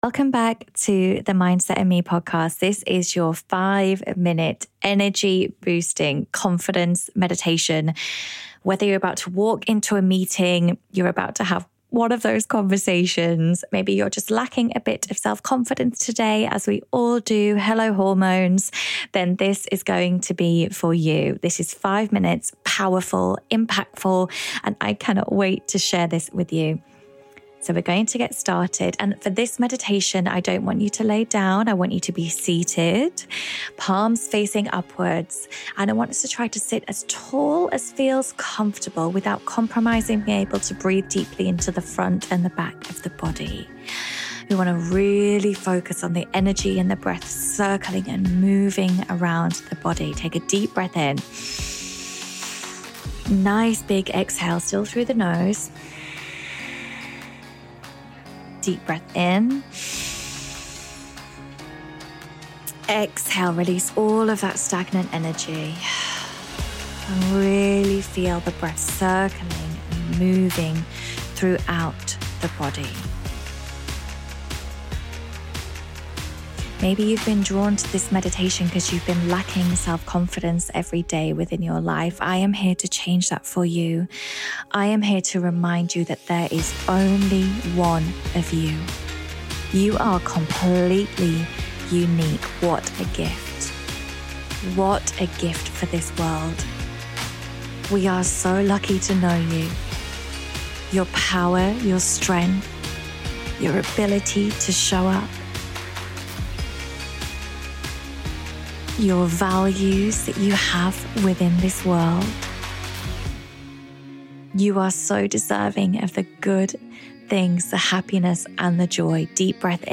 Confidence is something that we can lose so easily and as we get older the self belief and bravery of our youth can slip away. I really hope that this is a meditation that you can keep coming back to when you feel like you need a boost.